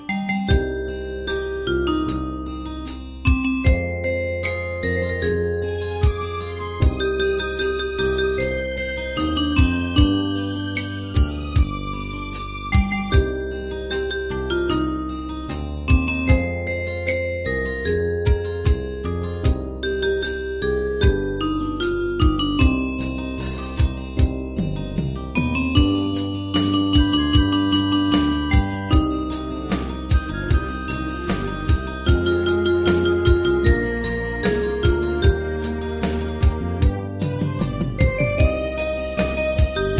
大正琴の「楽譜、練習用の音」データのセットをダウンロードで『すぐに』お届け！
Unison musical score and practice for data.
Tags: Japanese , Kayokyoku Enka .